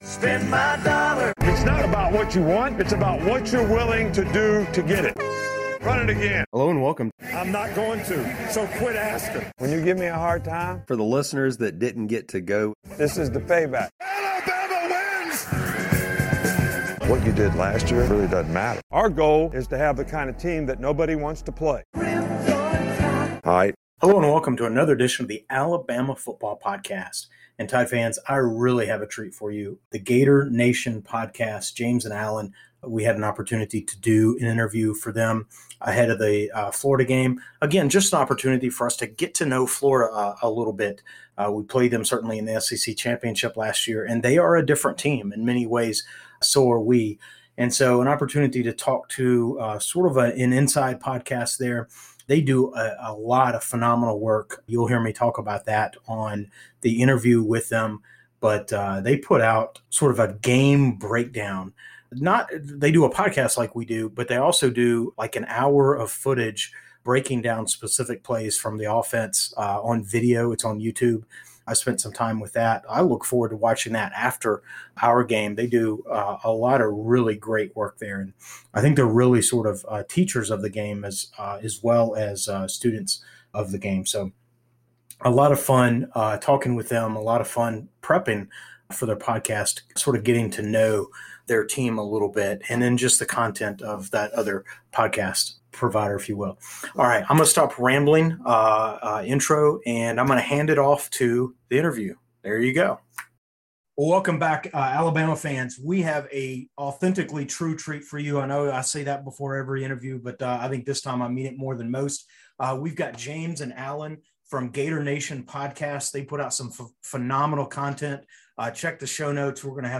2021AlabamaVsFloridaInterview.mp3